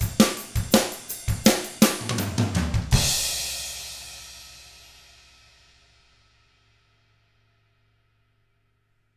164ROCK E1-R.wav